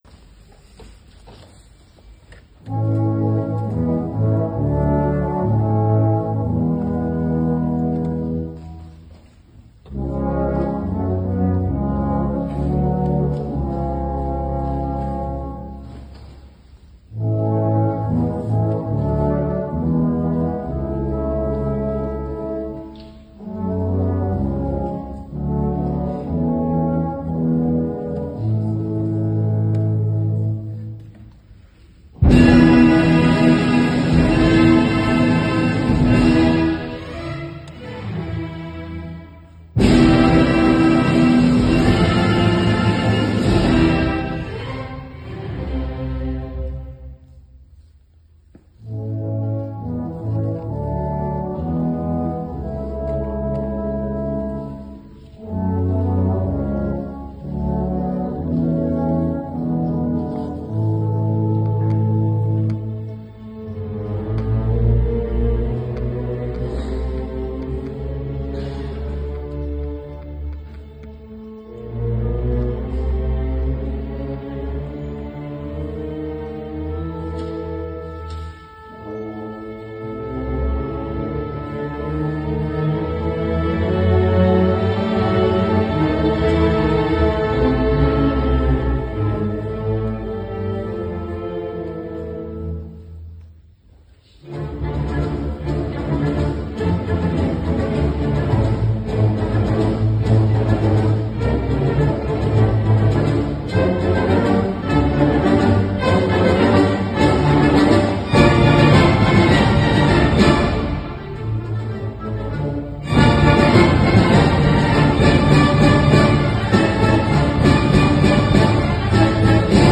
Extracts from the PSO 150th Anniversary Concert
by Verdi
LANDR-PSOVerdiOverturetoNabuco26Nov25-Balanced-Medium.wav